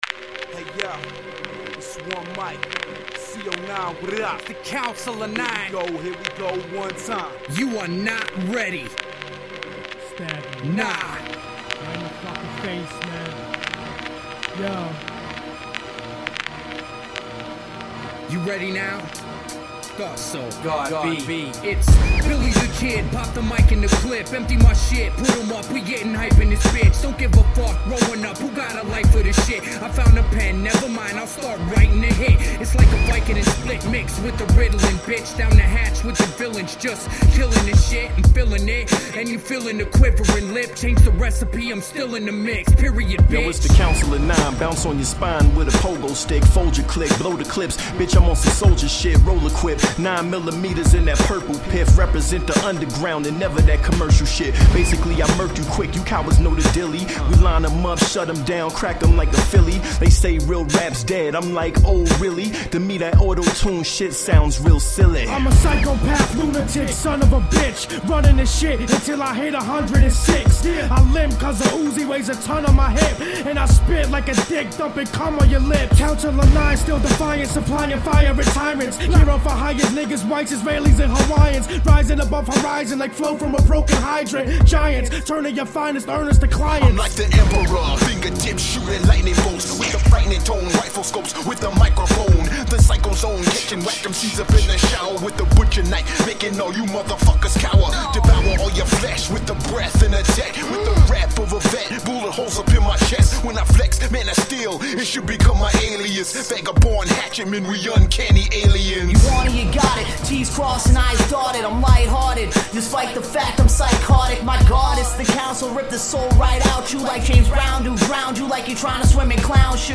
Award winning independent hip hop group.
Tagged as: Hip Hop, Funk